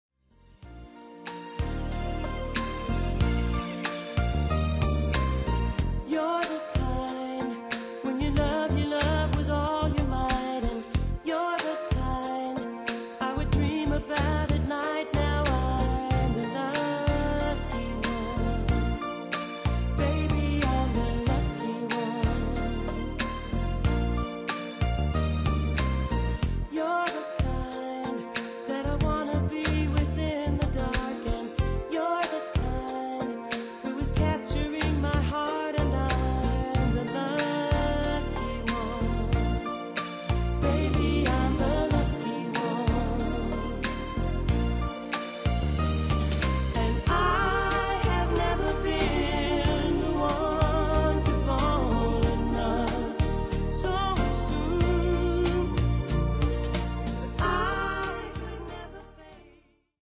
NOTE: Vocal Tracks 1 Thru 8